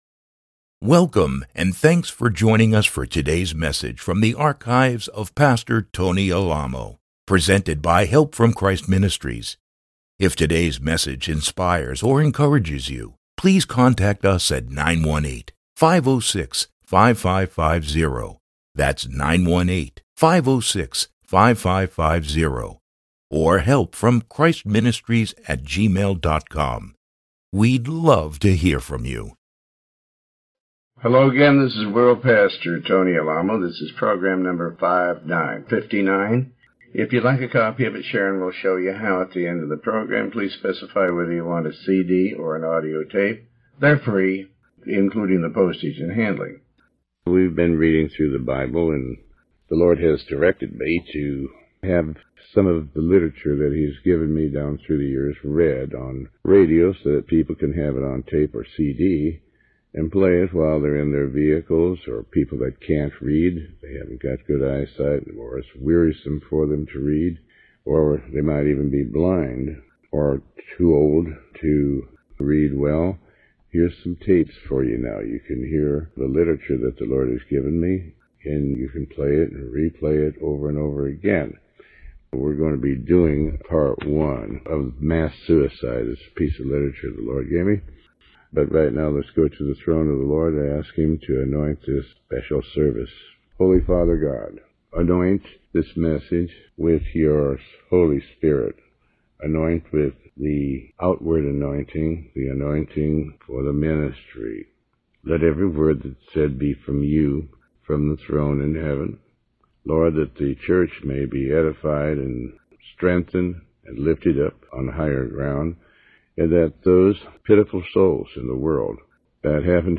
Sermon 59